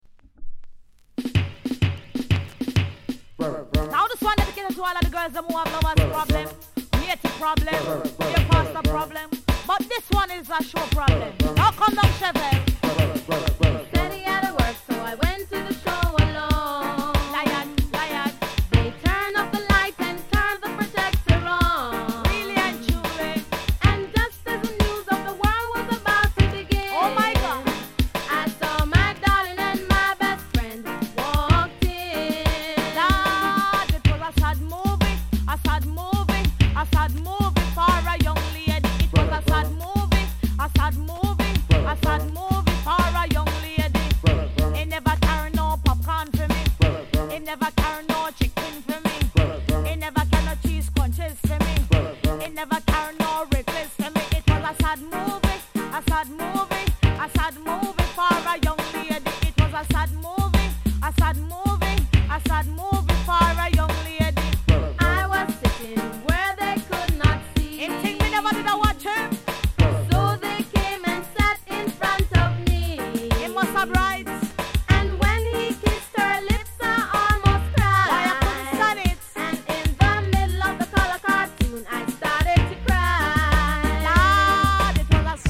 レゲエ